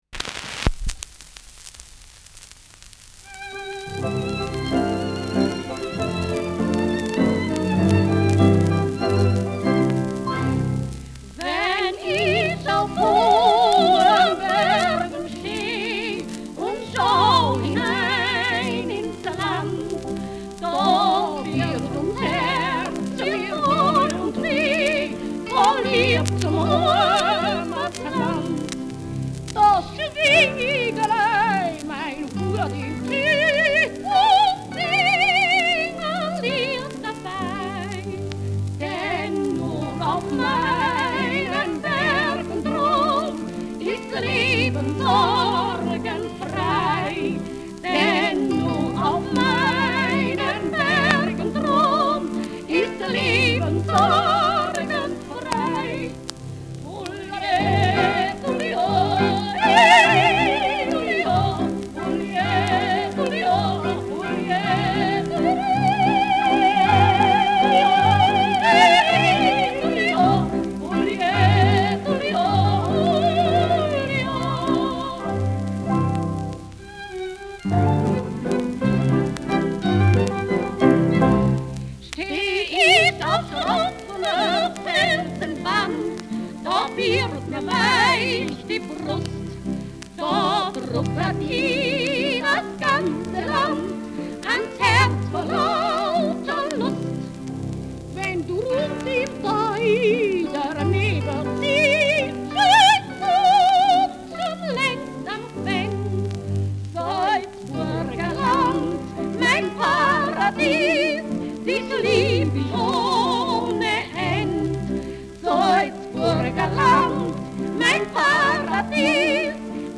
Schellackplatte
Tonrille: Kratzer Durchgehend Leicht : Kratzer 9 Uhr Stärker
Abgespielt : Gelegentlich leichtes bis stärkeres Knacken : Teils Nadelgeräusch
Georg Freundorfer mit seinem Instrumental-Ensemble (Interpretation)
Duo Schrögmeier (Interpretation)
[Berlin] (Aufnahmeort)
Stubenmusik* FVS-00016